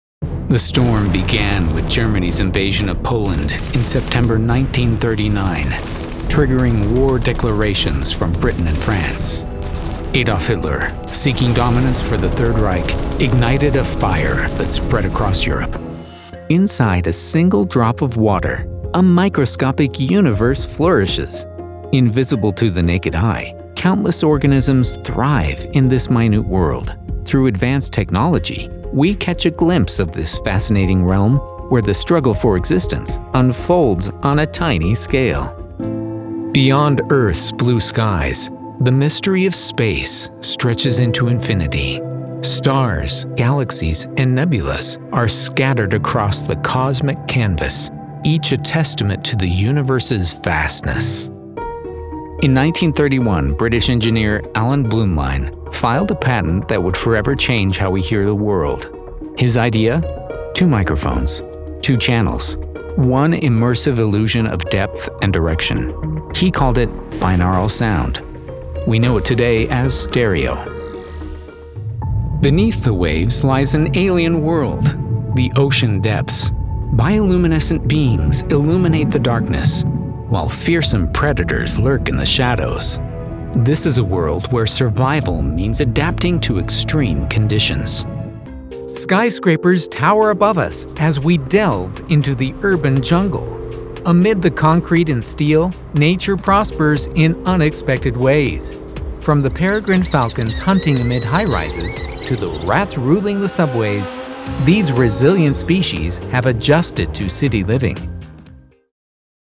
Documentary Narration Demo
Authoritative, Compelling, Trustworthy